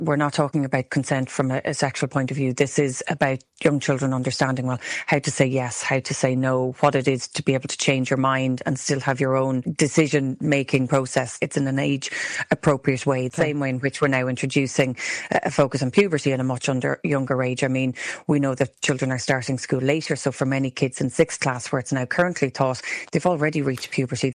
Education Minister Helen McEntee, says education around consent will be a big focus in the wellbeing specification: